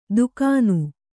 ♪ dukānu